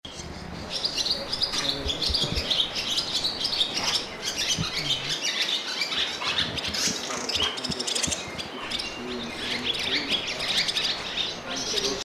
Barn Swallow
Barn swallows sing a variety of calls, both as an individual and as a group. They also make a clicking noise by snapping their jaws together.
barn-swallow-call.mp3